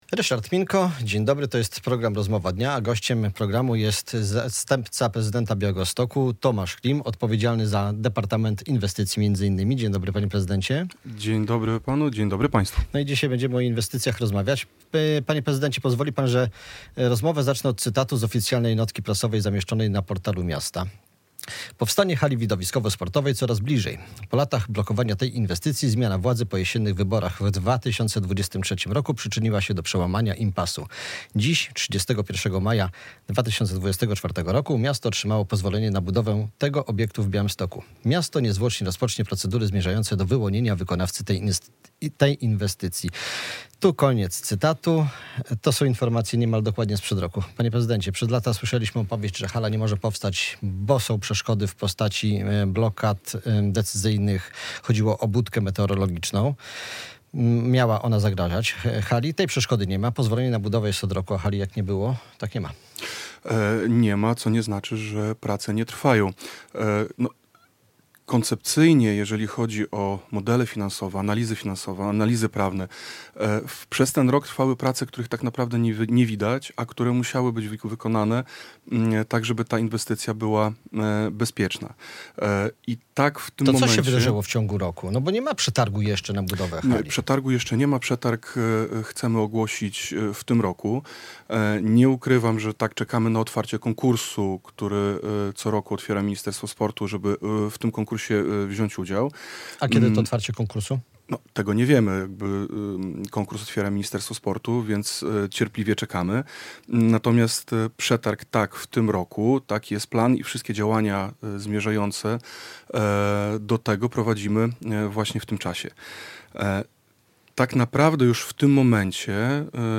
- Zrobimy wszystko, żeby tę halę wybudować. Ona musi powstać - białostoczanie oczekują tej inwestycji, białostockie kluby oczekują tej inwestycji - mówił w Polskim Radiu Białystok o budowie hali widowiskowo-sportowej w Białymstoku zastępca prezydenta miasta Tomasz Klim.